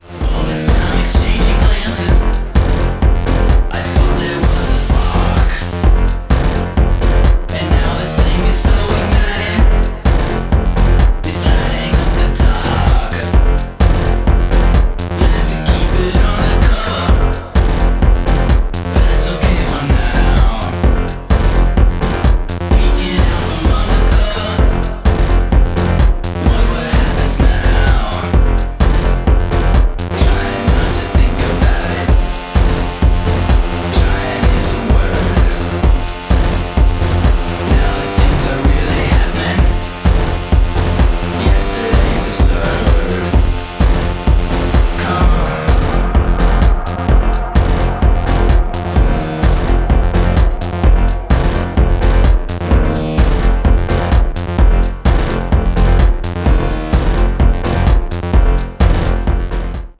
EBM/Industrial